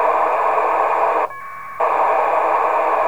APRS на 144.800 МГц, шумодав выключен
Начало » Записи » Радиоcигналы классифицированные